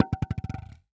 Звуки пенопласта
Маленький кусочек пенопласта уронили на пол